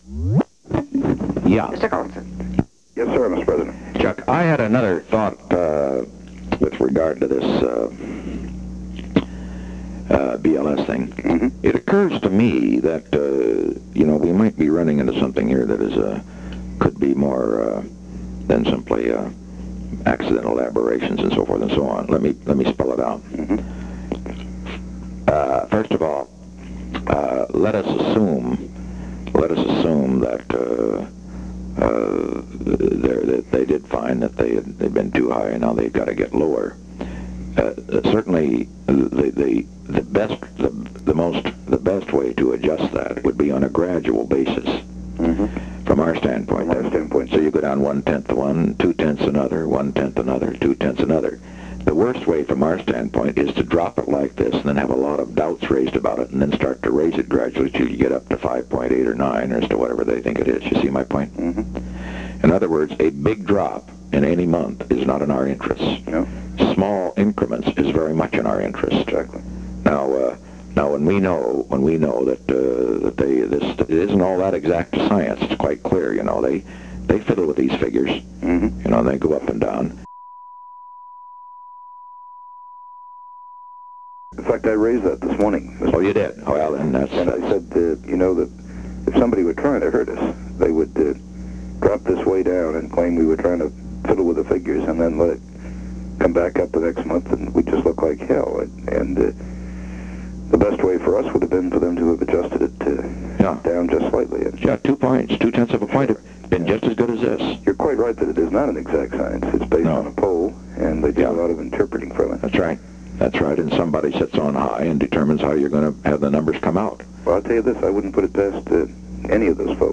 Secret White House Tapes
Location: White House Telephone
The President talked with Charles W. Colson.